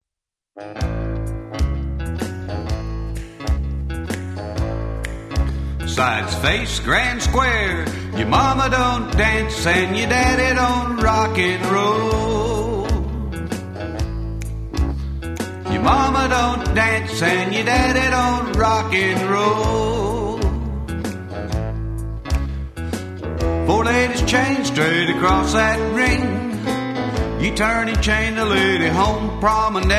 Vocal